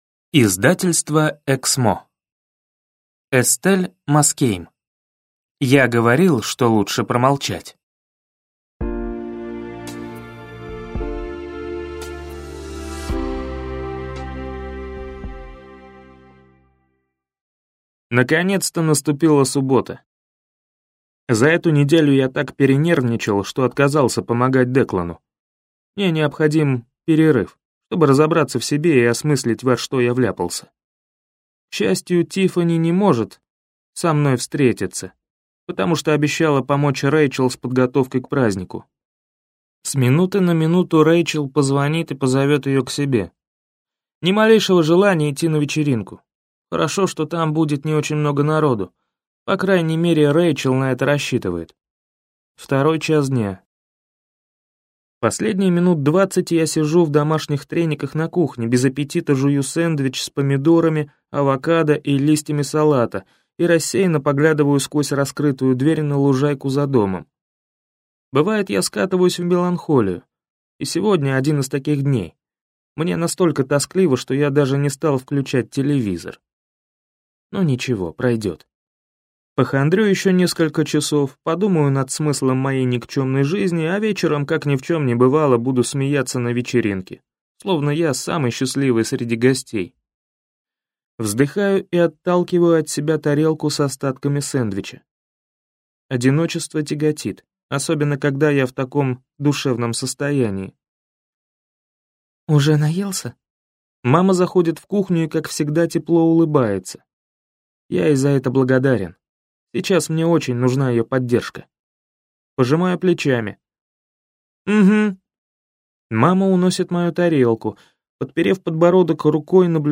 Aудиокнига Я говорил, что лучше промолчать?